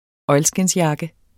Udtale [ ˈʌjlsgens- ]